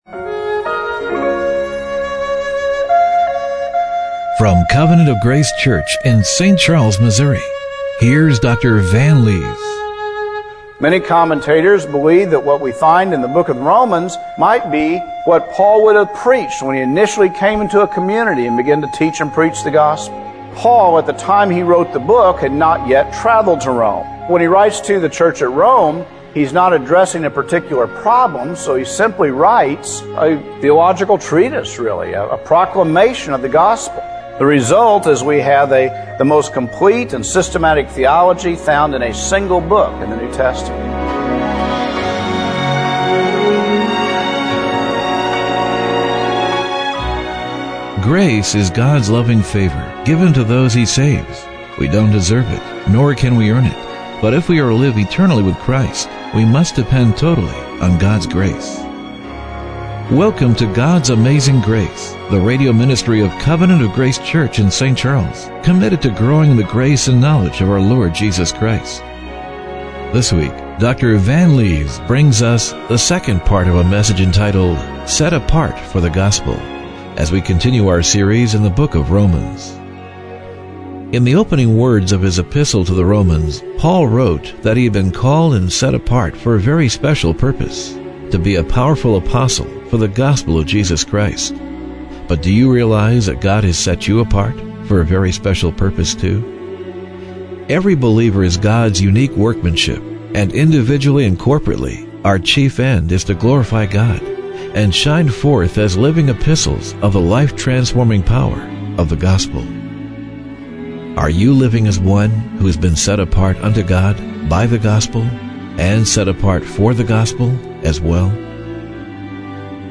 Romans 1:1-4 Service Type: Radio Broadcast Are you living as one who has been set apart unto God by the Gospel